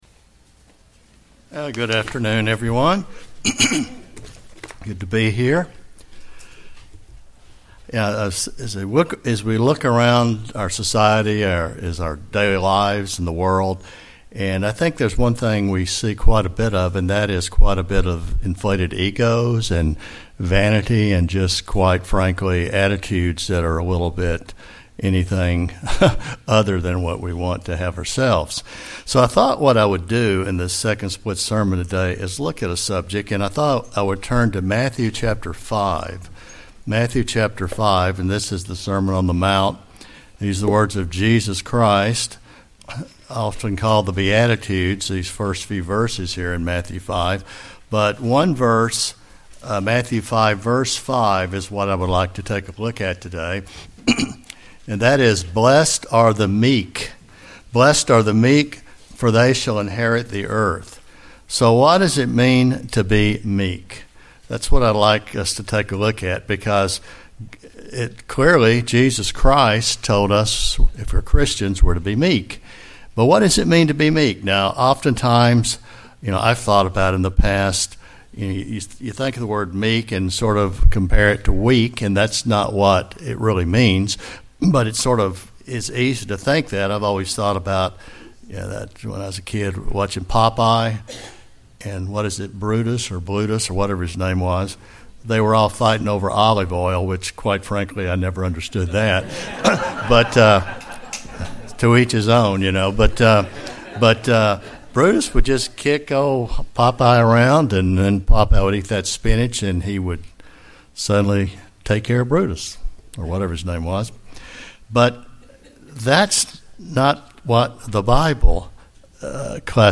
The Bible stresses the importance of meekness for a Christian. Today's sermon considers the importance of meekness in the life of a Christian.